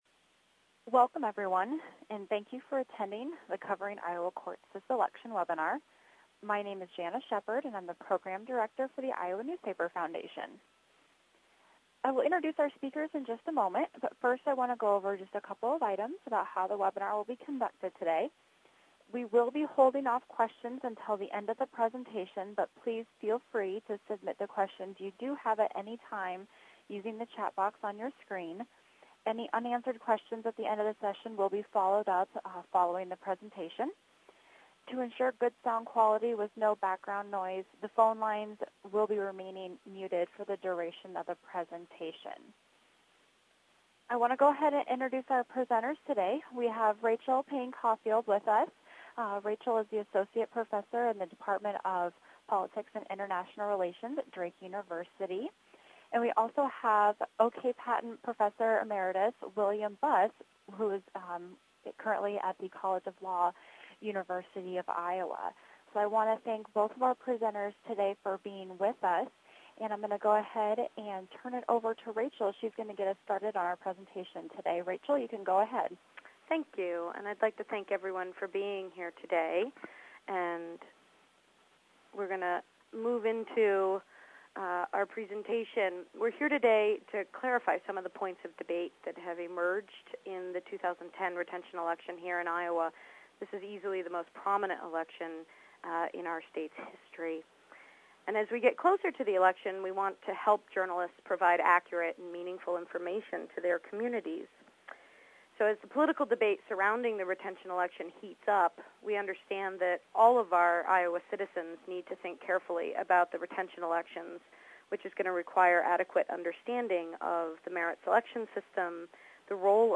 The Iowa Bar Association and three other groups hosted an hour-long discussion this afternoon about the upcoming judicial retention elections in Iowa.
CourtWebinar.mp3